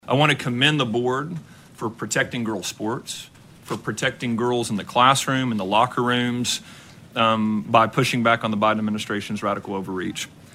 CLICK HERE to listen to commentary from State School Superintendent Ryan Walters.
State School Superintendent Ryan Walters launched the meeting of the state School Board with a rant of complaints about the Biden Administration.